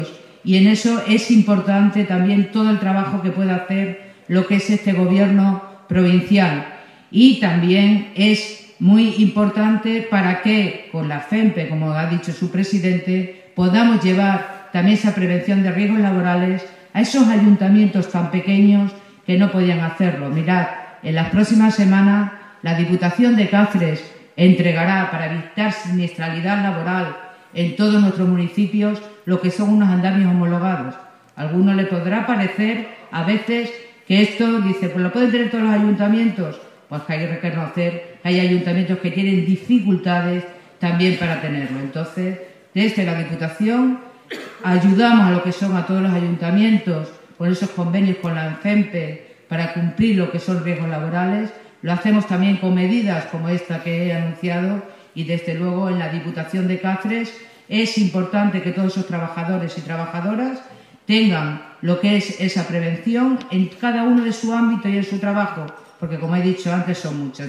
CORTES DE VOZ
En un acto que ha tenido lugar en Mérida y de manos del Presidente de la Junta de Extremadura, Guillermo Fernández Vara, Rosario Cordero ha recibido el reconocimiento en nombre de todo el personal del Servicio de Prevención, con su diputado al frente, Miguel Salazar, que acompañado por miembros de su equipo también han asistido al acto de entrega de los II Premios de Fomento de la Seguridad y Salud en el Trabajo.